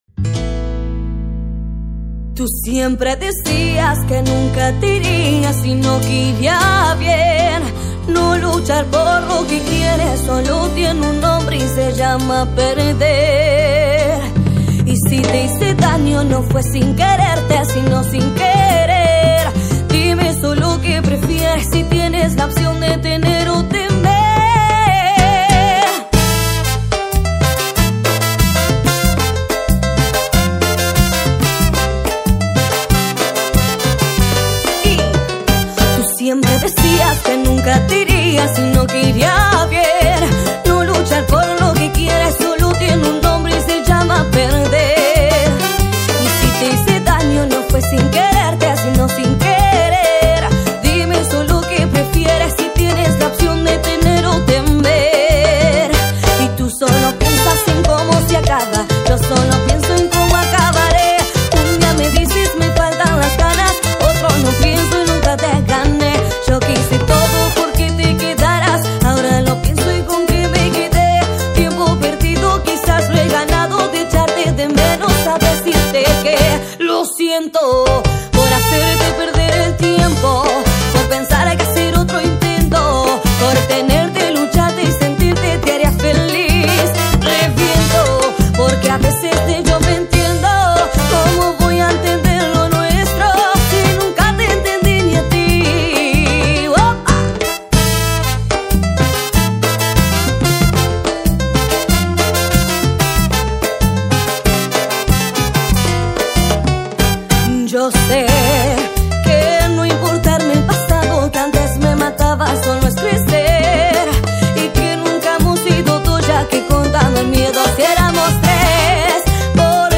Carpeta: Cumbia y + mp3
en vivo